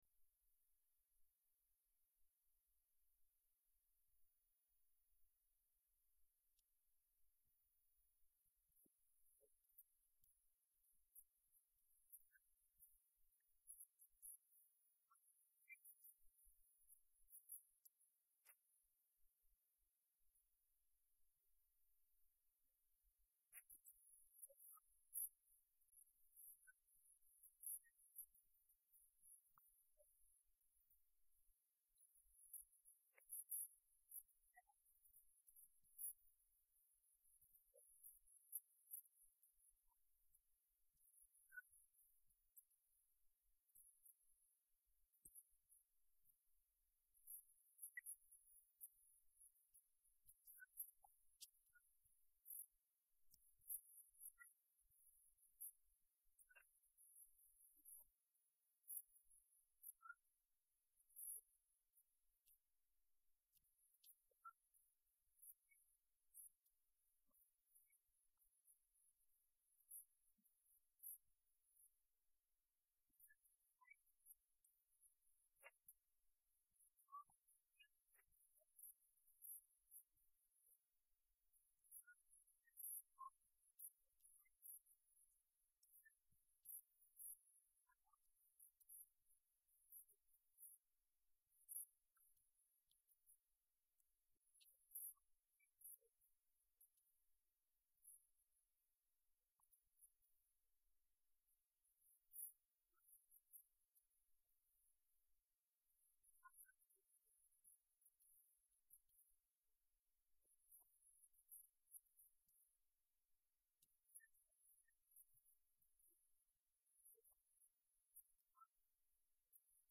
A message from the series “Making Our Way Back.”…